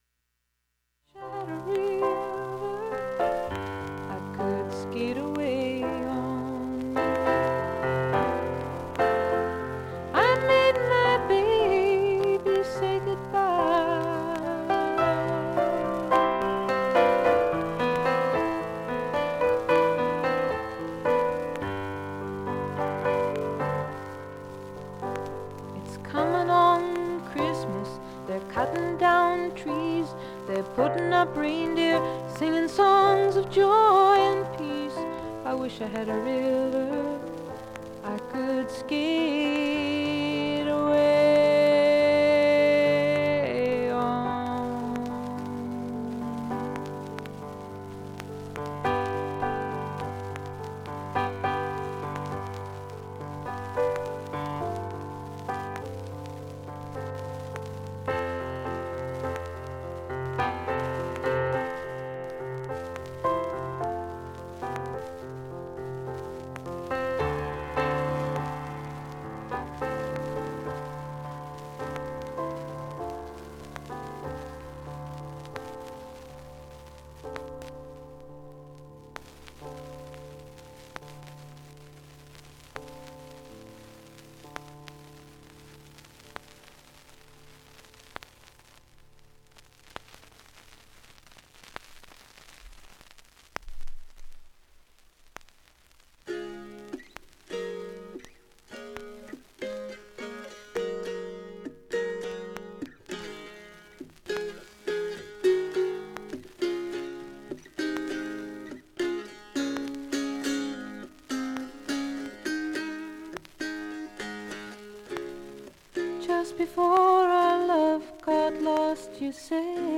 ６分の間に周回プツ出ますがかすかです、
B-4後半からはかなりかすかで、
現物の試聴（スレ部すべて上記録音時間６分）できます。音質目安にどうぞ
５回までのかすかなプツが２箇所
３回までのかすかなプツが５箇所
単発のかすかなプツが１１箇所